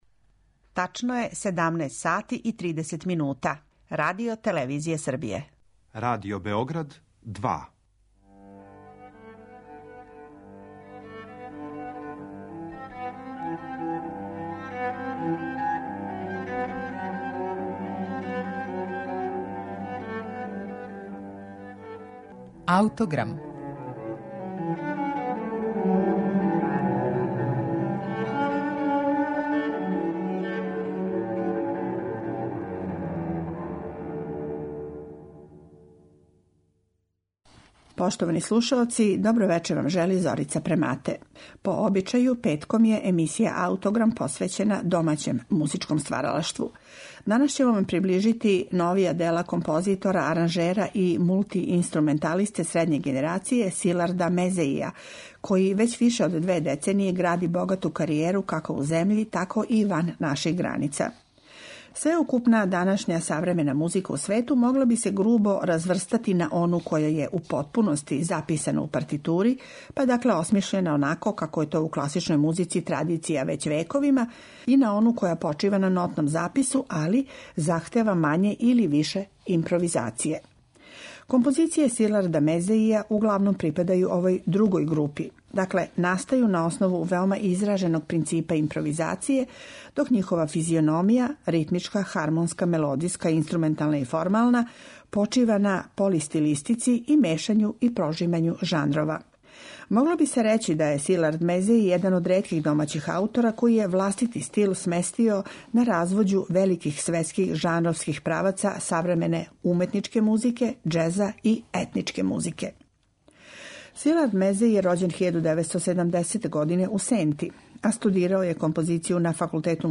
Слушаћете премијерно извођење његовог Концерта за флауте, односно за пиколо, це-флауту, алт-флауту и бас-флауту, насталог 2016. године.